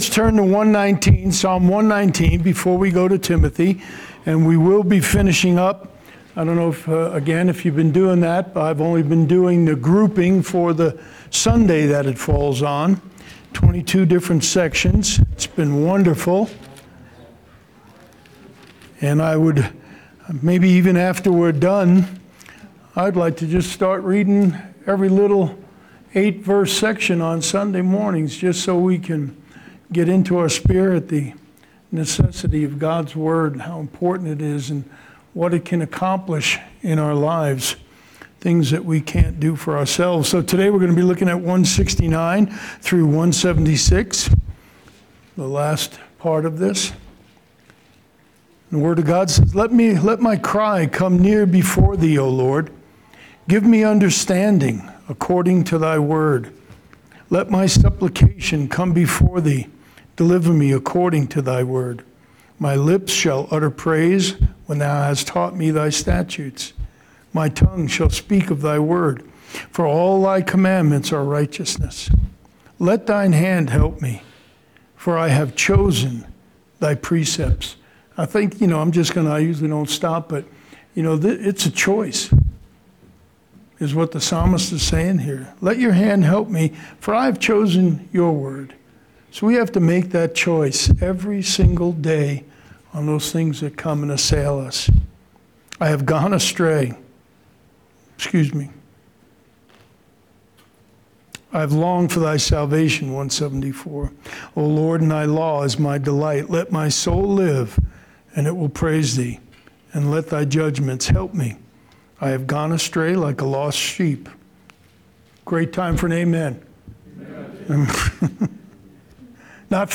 January 24th, 2021 Sermon